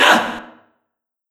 crowd_na_jp.wav